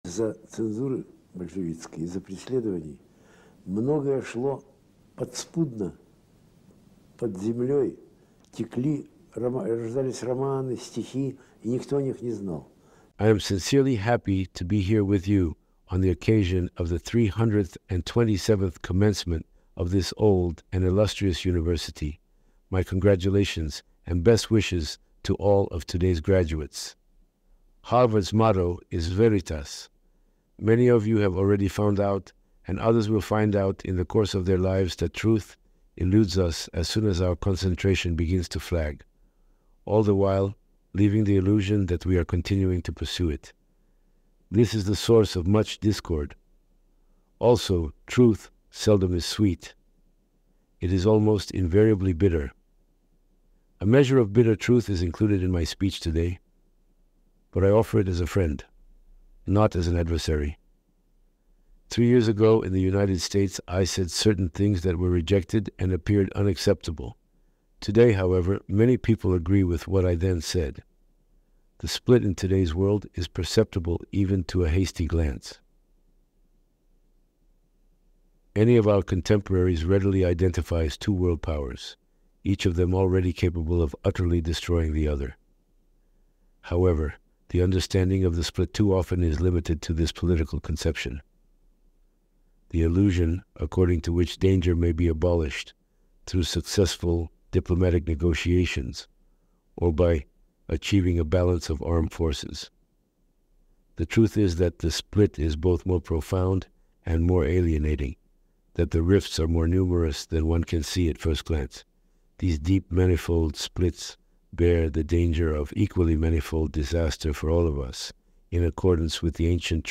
Aleksandr Solzhenitsyn Harvard Address in English AI Reconstruction